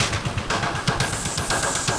Index of /musicradar/rhythmic-inspiration-samples/120bpm
RI_DelayStack_120-04.wav